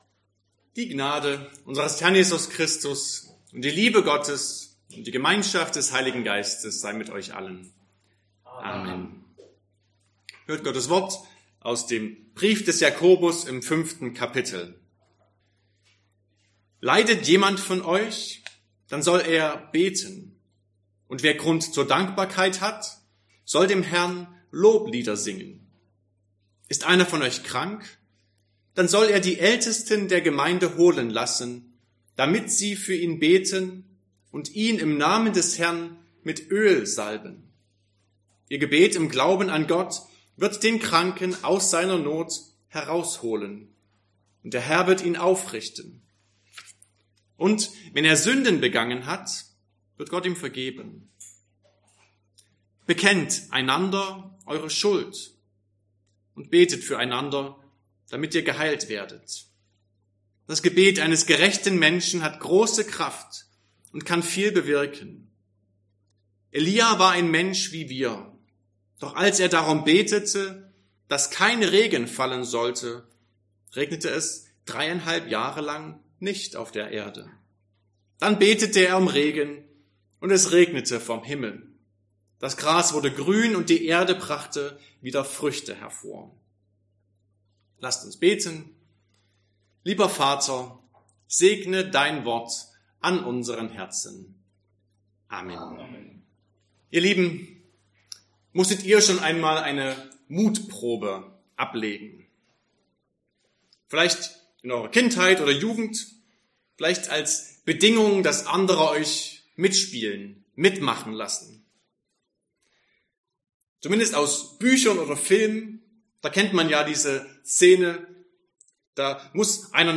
Sonntag nach Trinitatis Passage: Jakobus 5, 13-18 Verkündigungsart: Predigt « 7.